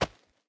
footstep sounds
DIRT_2.ogg